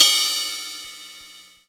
Ride Cym 2.wav